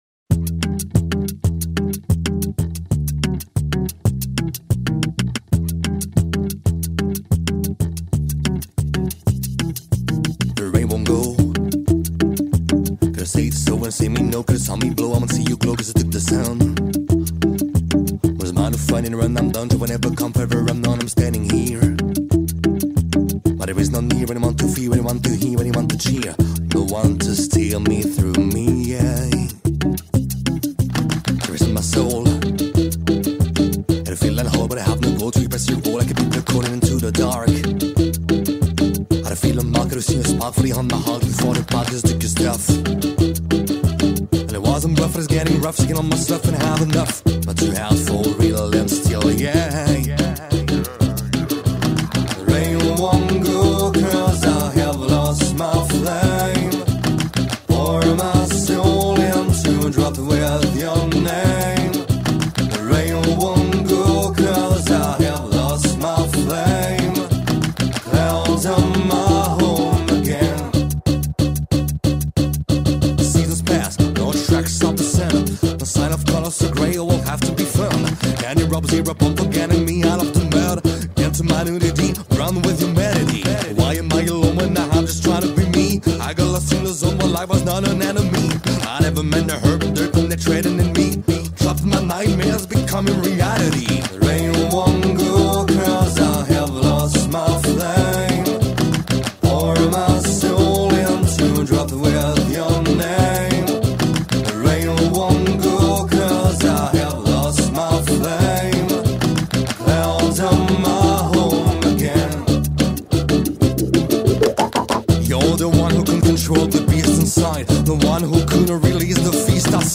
interview & musique